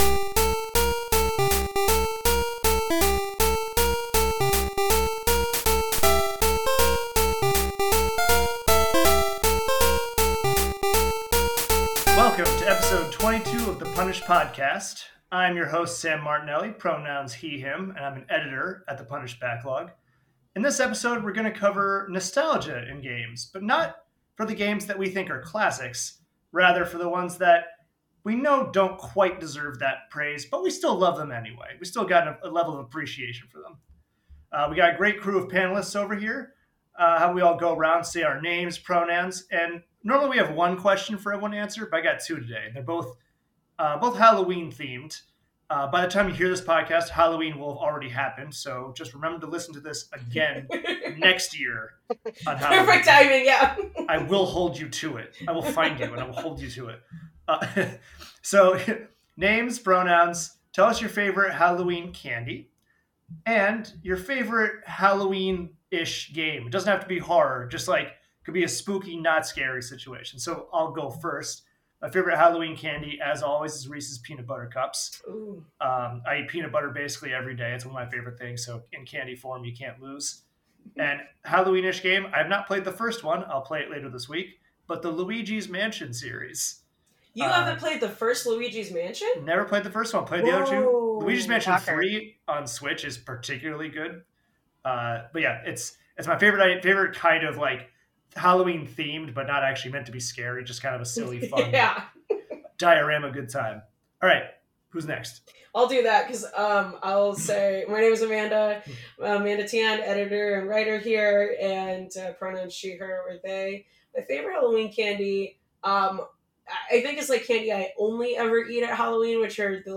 Apple Podcasts Note: This episode has light swearing.